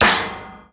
wrench_hit_metal2.wav